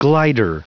Prononciation du mot glider en anglais (fichier audio)
Prononciation du mot : glider